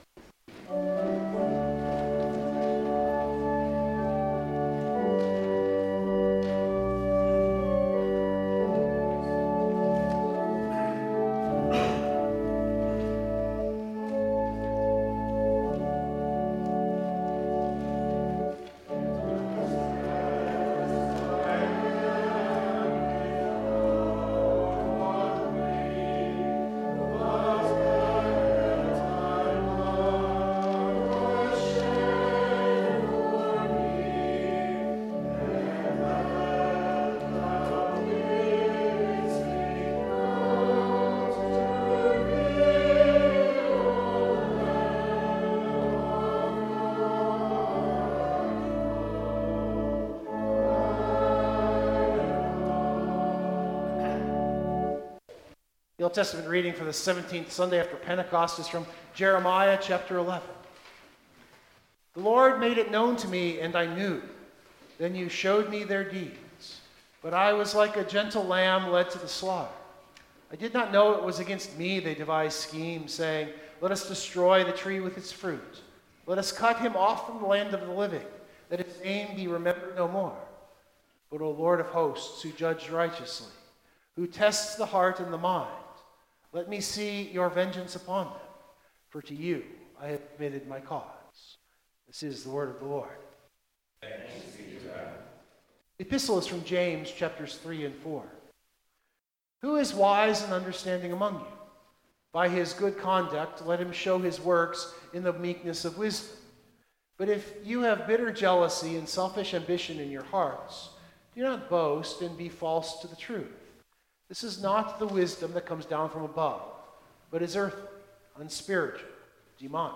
Biblical Text: Luke 4:31-44 Full Sermon Draft